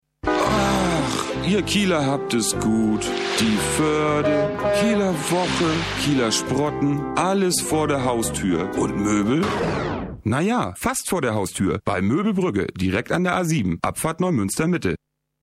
deutscher Stimmenimitator, Sprecher, Entertainer, Musiker..
Sprechprobe: Industrie (Muttersprache):